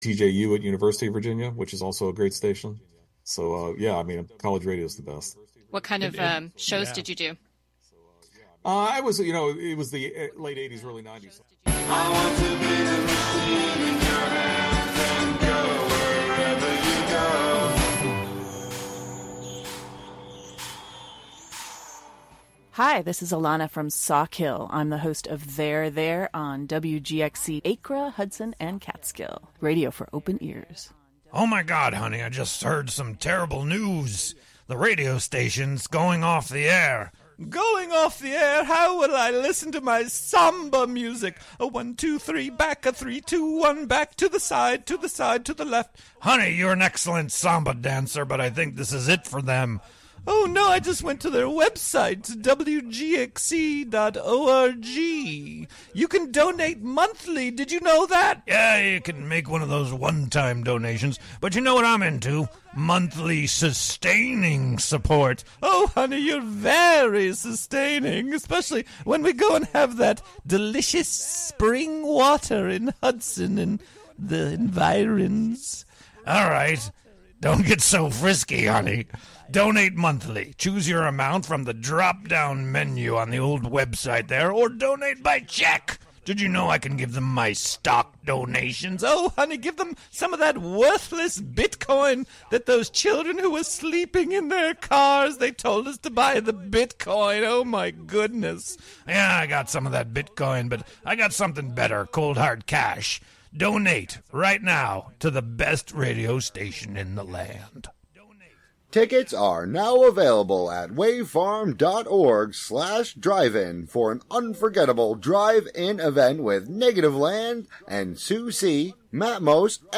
Contributions from many WGXC programmers.
The "WGXC Morning Show" features local news, interviews with community leaders and personalities, a rundown of local and regional events, weather updates, and more about and for the community. The show is a place for a community conversation about issues, with music, and more. Saturday the emphasis is more on radio art, and art on the radio.